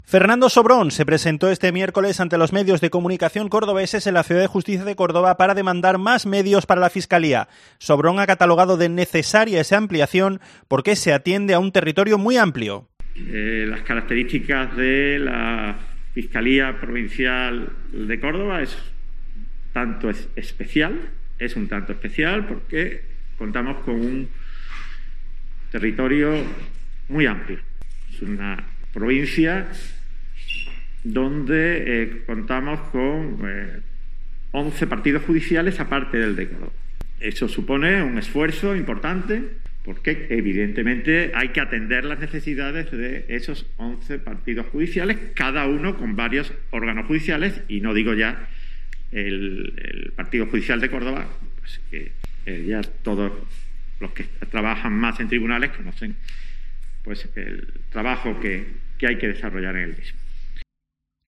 Fernando Sobrón, nuevo Fiscal Jefe de Córdoba, se presentó este miércoles ante los medios de comunicación cordobeses en la Ciudad de la Justicia de Córdoba para demandar más medios para la Fiscalía. Sobrón ha catalogado de “necesaria” esa ampliación del número fiscales porque se atiende a un territorio muy amplio con once partidos judiciales y la capital.